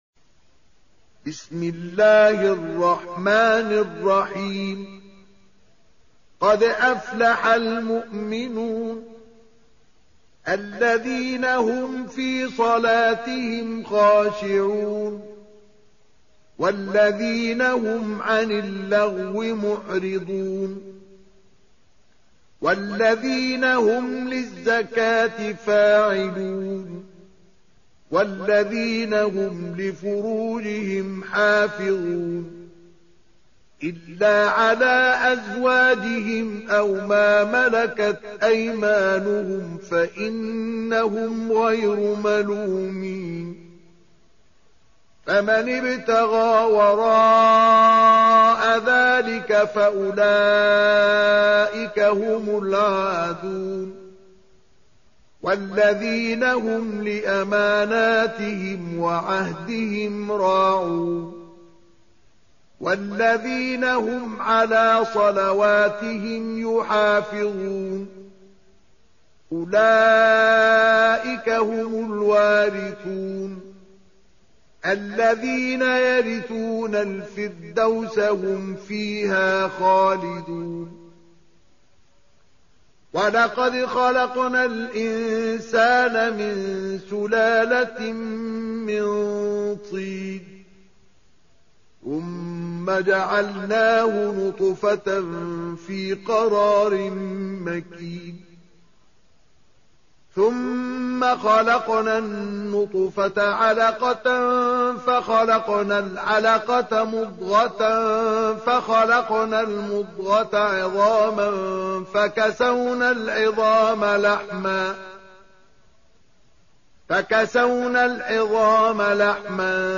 23. Surah Al-Mu'min�n سورة المؤمنون Audio Quran Tarteel Recitation Home Of Sheikh Mustafa Ismail
Surah Sequence تتابع السورة Download Surah حمّل السورة Reciting Murattalah Audio for 23.